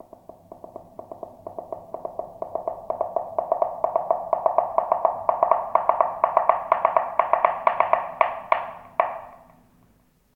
Galope de caballo
Sonidos: Animales Mamíferos